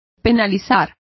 Complete with pronunciation of the translation of penalizes.